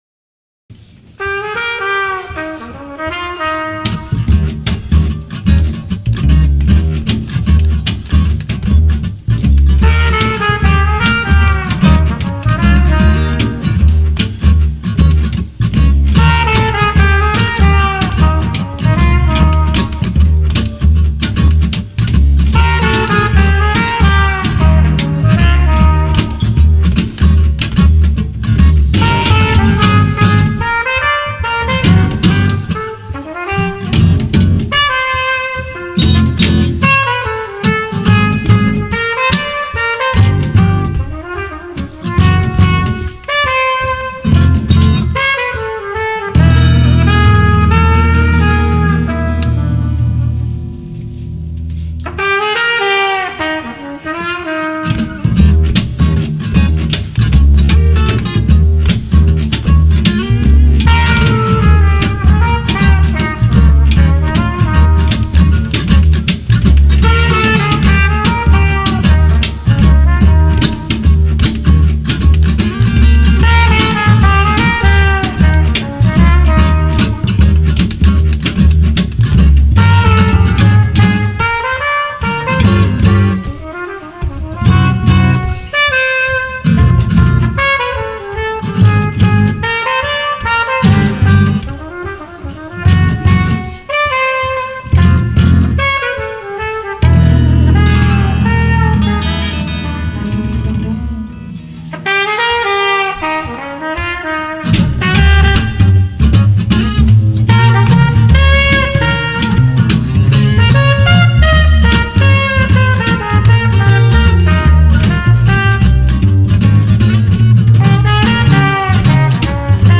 The originality that is shared at the monthly RTTM events is a clear view into the future of Philly jazz.
RTTM Performance Audio Samples
Chance Trio 1/24/03 at Highwire Gallery